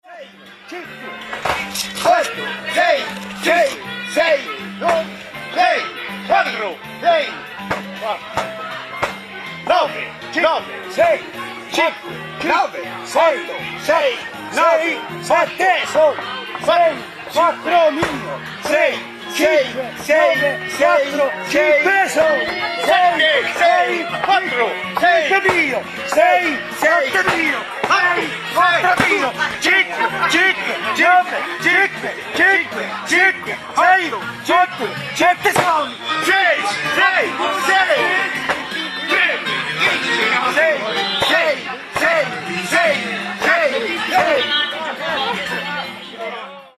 The summer festas is the time of year where an extremely old game is sometimes played by men of the area.